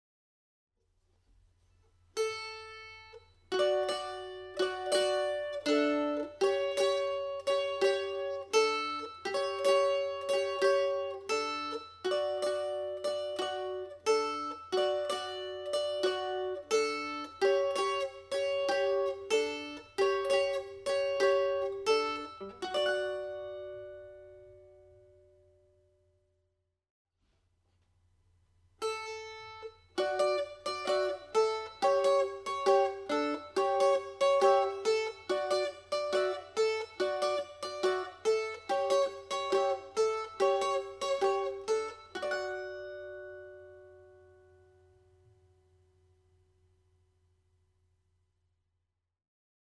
Polka (D Major)
Exit Riff (chord version)